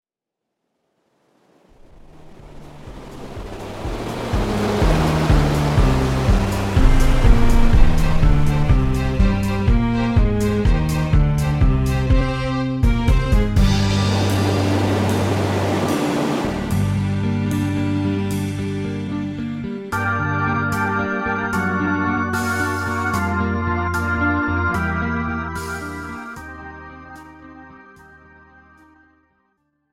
Country , Pop , Rock